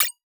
Arcade Game Button Tap.wav